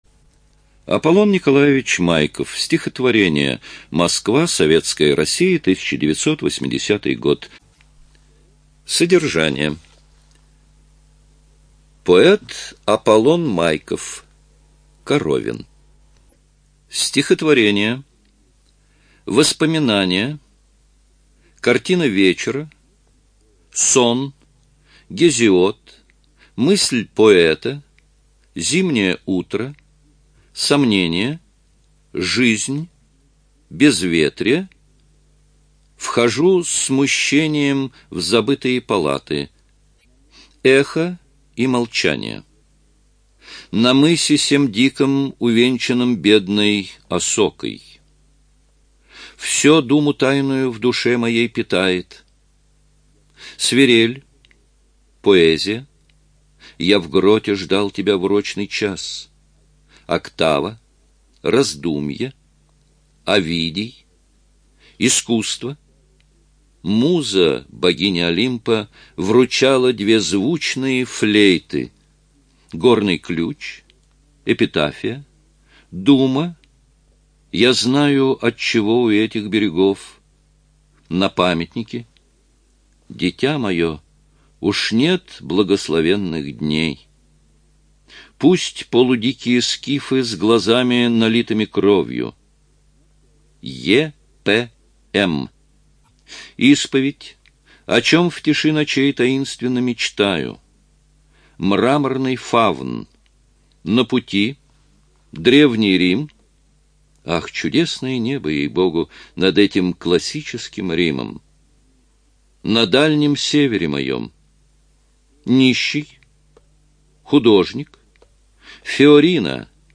ЖанрПоэзия
Студия звукозаписиЛогосвос